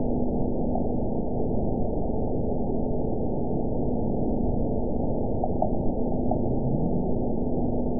event 917080 date 03/18/23 time 23:44:08 GMT (1 year, 1 month ago) score 8.82 location TSS-AB03 detected by nrw target species NRW annotations +NRW Spectrogram: Frequency (kHz) vs. Time (s) audio not available .wav